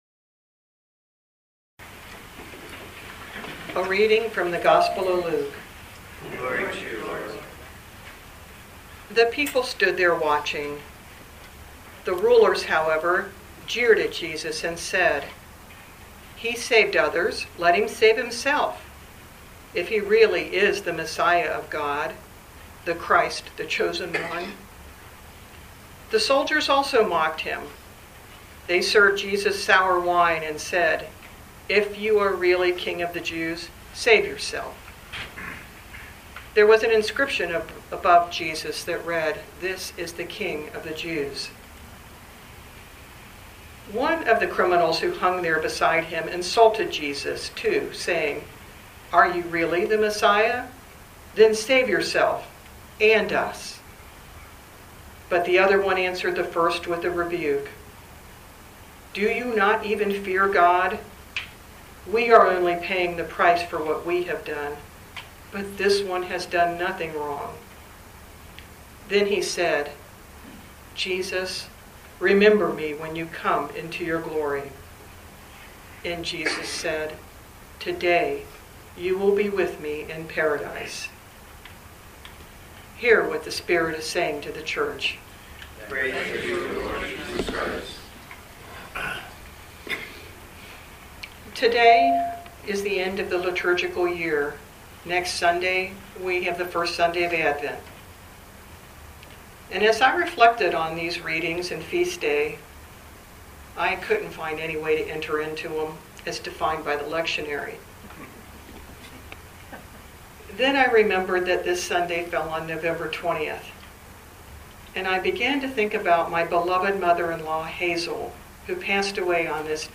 Living Beatitudes Community Homilies: Proximity Matters